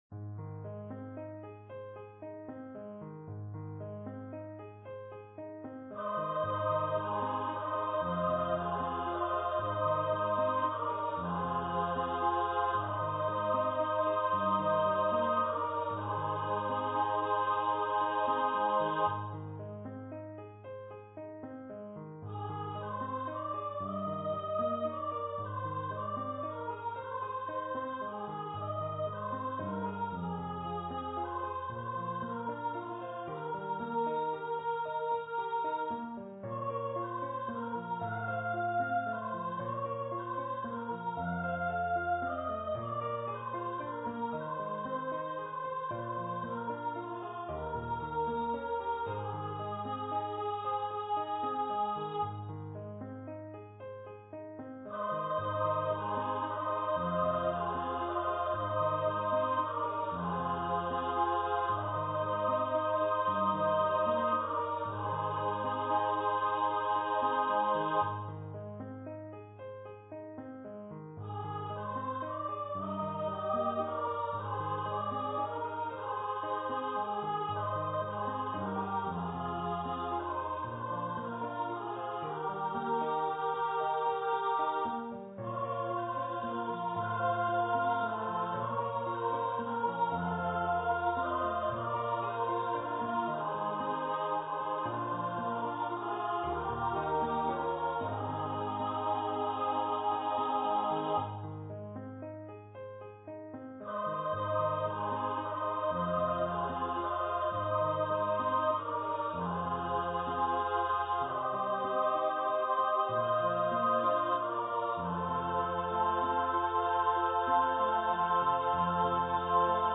for upper voice choir and piano
Choir - 3 part upper voices